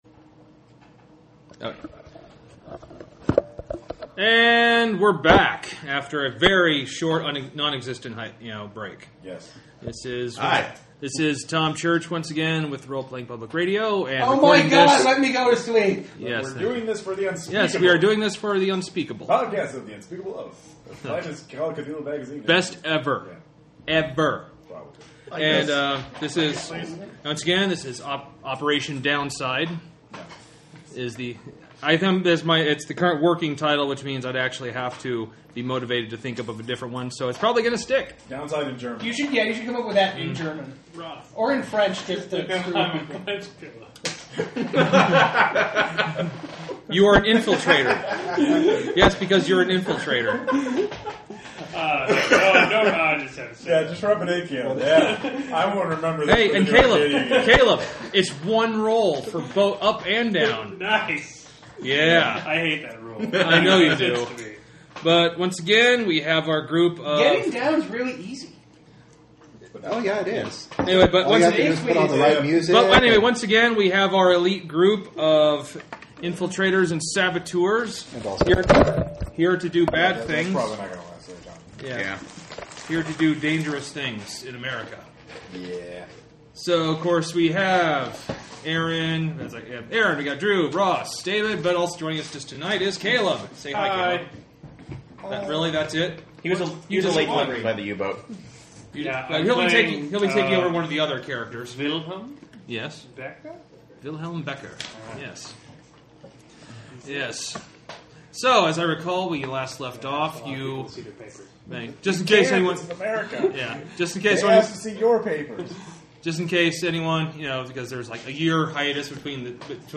Unspeakable! Actual Play Podcast Episode 6 – Operation Downside (Part 2 of 4)